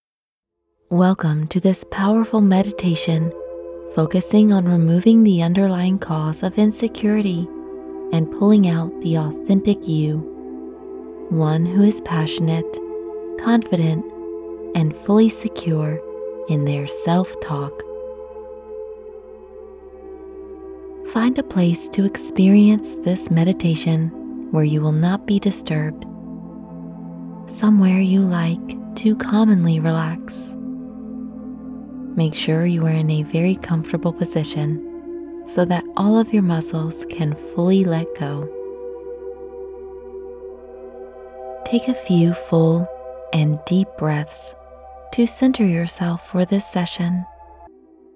Through our hypnosis audio, we can help you reclaim your strength and overcome those nagging insecurities today.